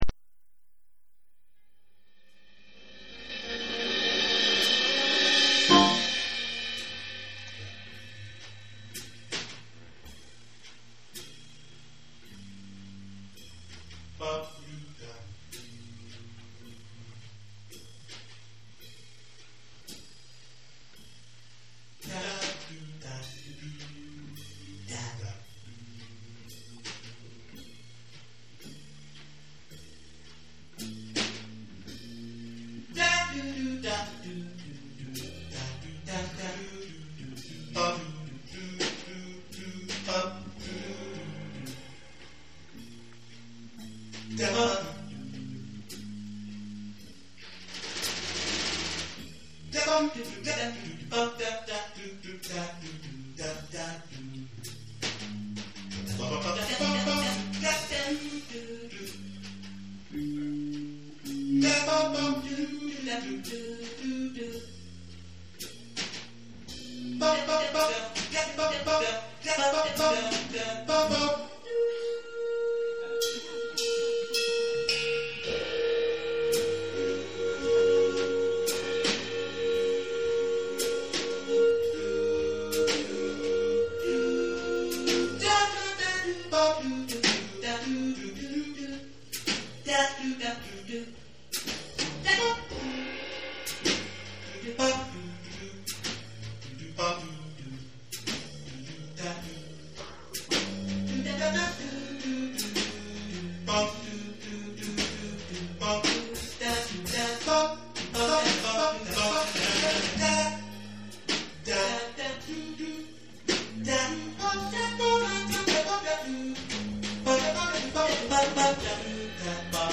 voici un mini enregistrement de live par mes soins